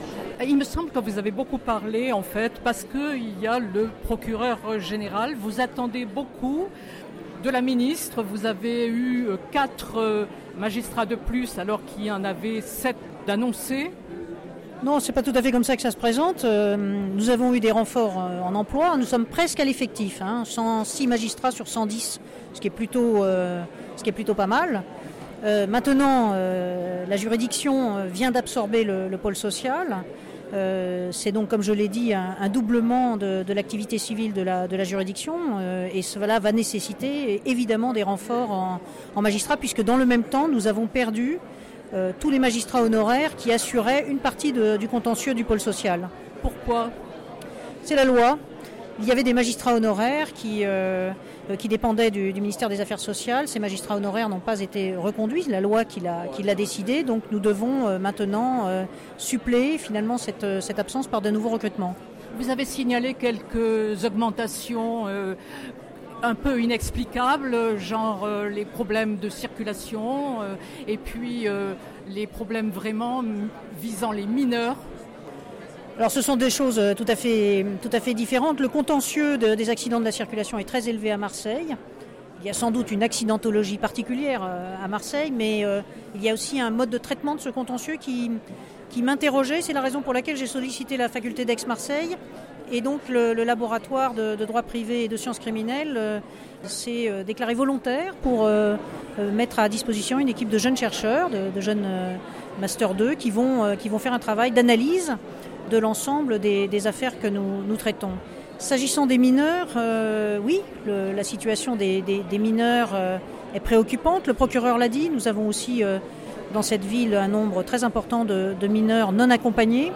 Magistrats, représentants de l’État, autorités civiles et militaires, acteurs du monde judiciaire, se sont réunis au sein Tribunal de grande instance de Marseille pour l’audience solennelle de rentrée 2019.
isabelle_gorce_18_01_19.mp3